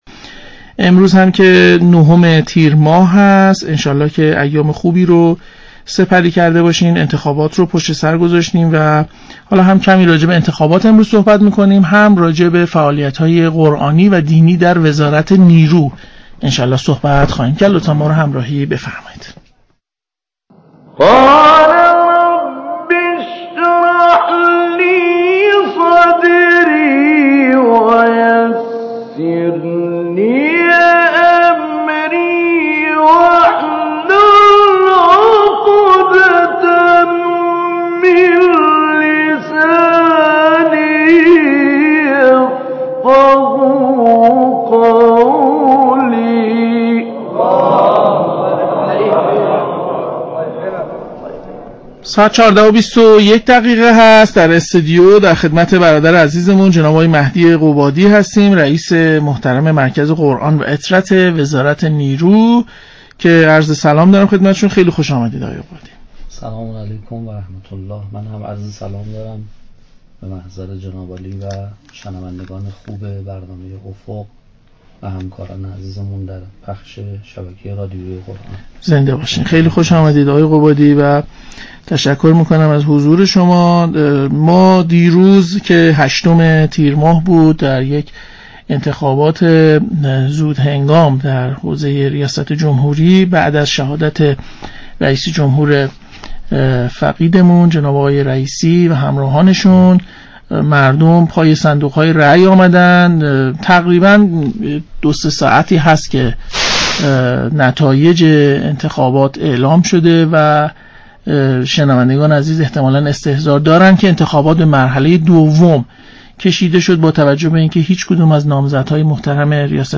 در قالب ارتباط تلفنی